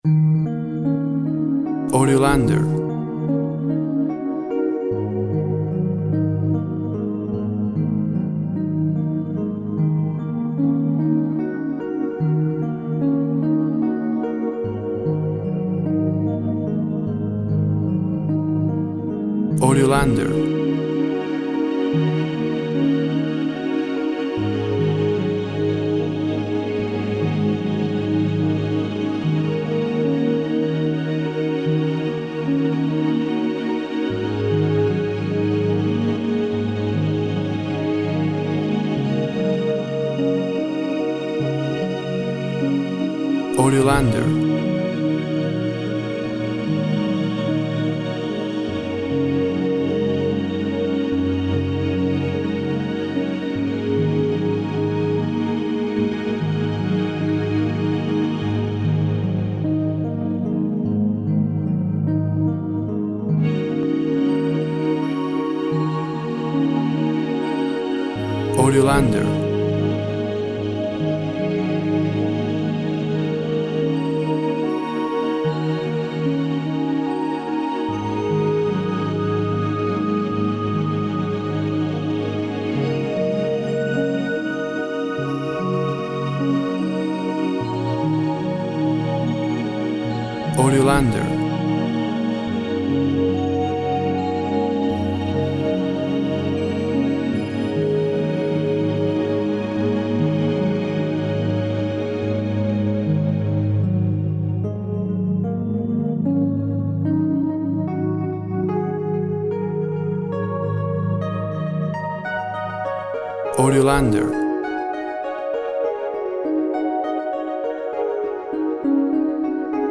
Synth harp and strings create a relaxing atmosphere.
Tempo (BPM) 72